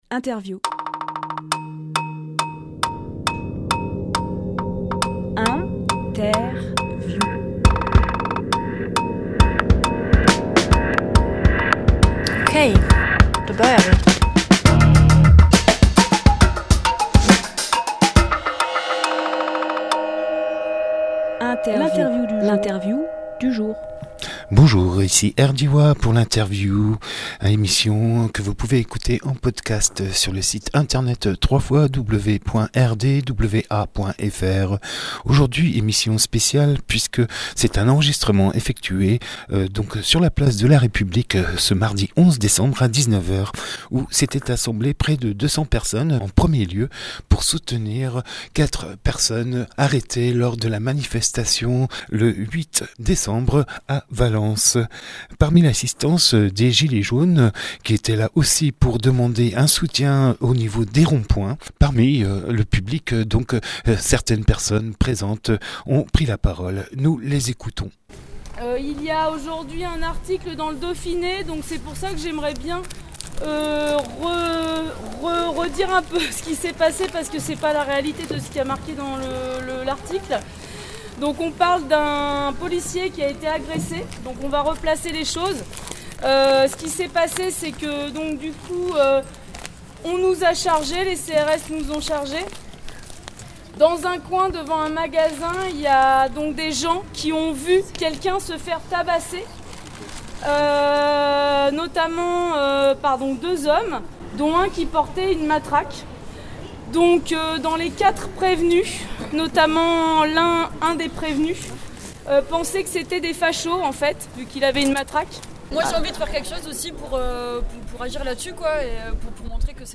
Emission - Interview Assemblée à Die contre quatre incarcérations Publié le 12 décembre 2018 Partager sur… Télécharger en MP3 Place de la République de Die, mardi 11 décembre à 19h.
Tour à tour, des voix expriment leur colère, leur incompréhension ou dénoncent l’organisation systématique de la violence par les forces de l’ordre à l’encontre des gilets jaunes, mais aussi des espoirs.